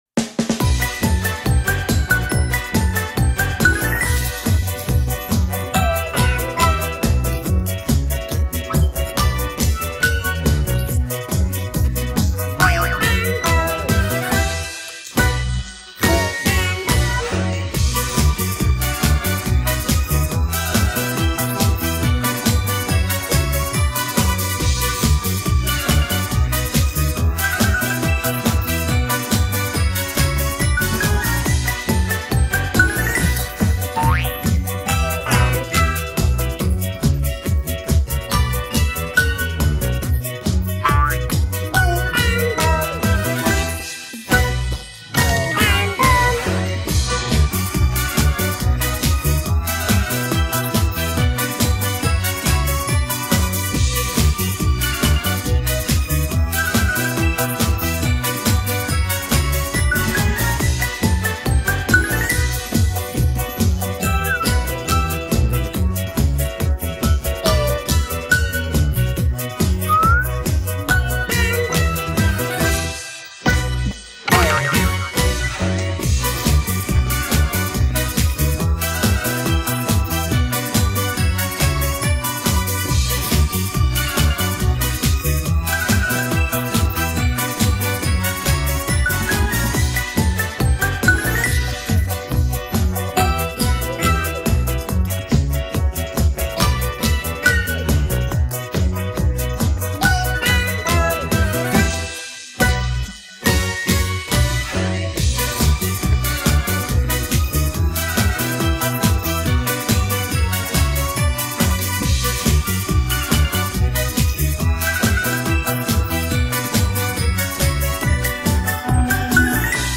לא הכי איכותי אבל חינמי!